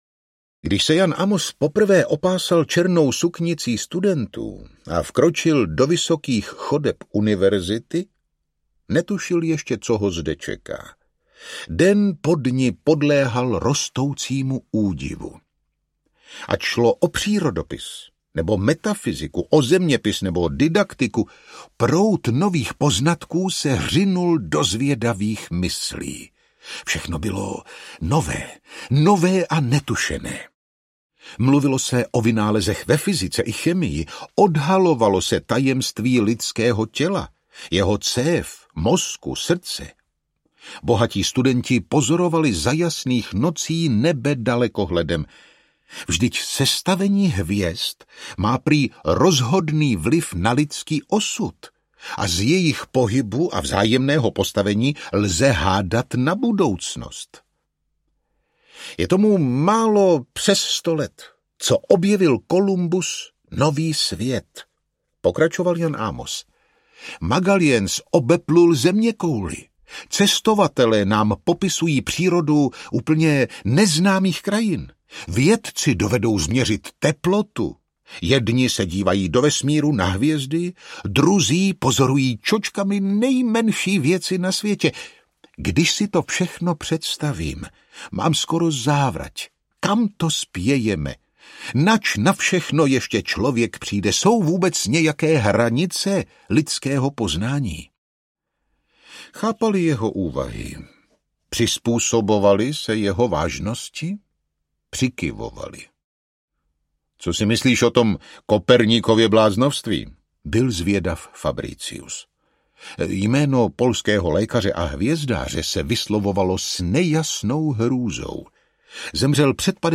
Anděl míru audiokniha
Ukázka z knihy
Vyrobilo studio Soundguru.